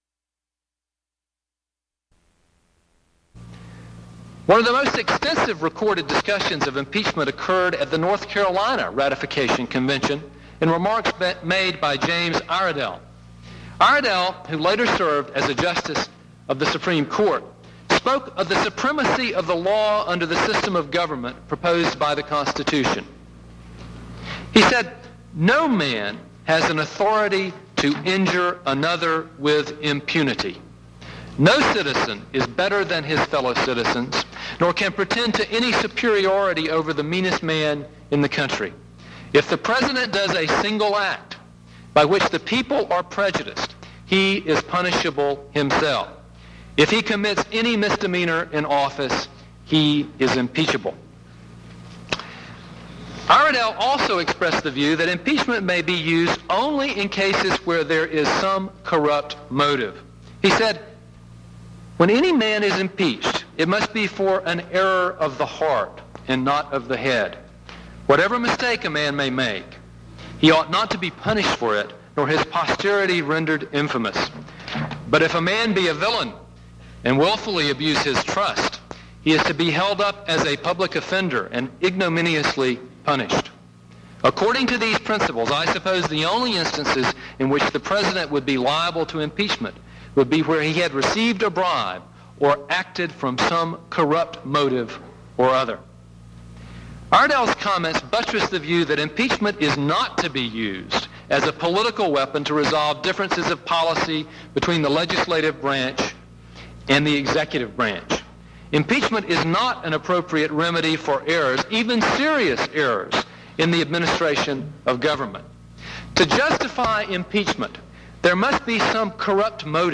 Charles Canady (R-FL) concludes his testimony in the impeachment of President Clinton